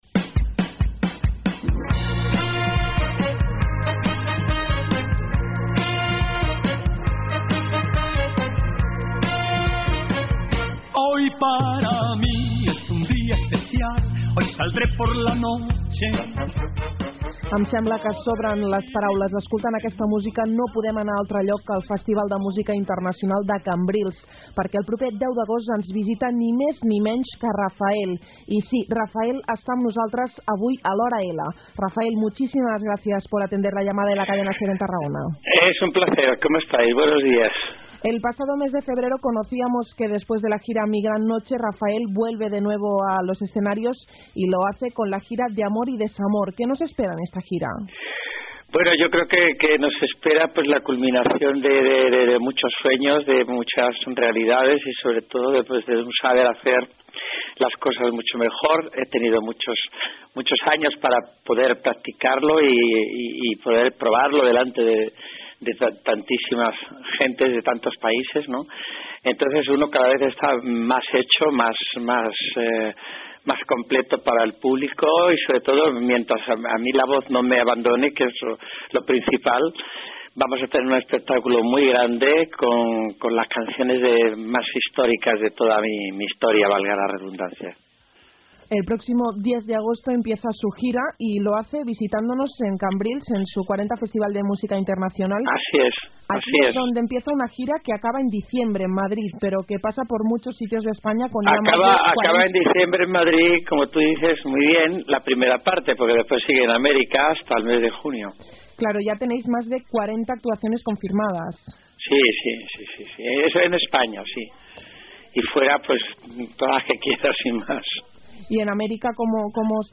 Raphael reportaje por Cadenaser